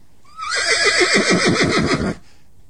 PixelPerfectionCE/assets/minecraft/sounds/mob/horse/idle1.ogg at mc116